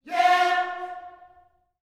YEAH F 4E.wav